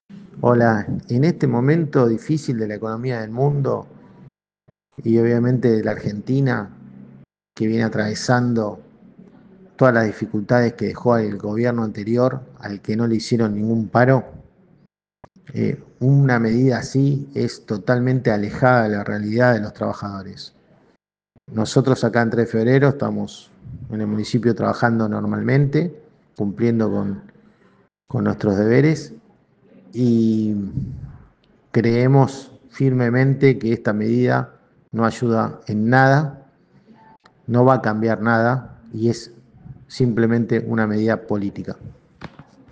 Desde Argentina Política, conversamos con diferentes actores afines al gobierno Nacional.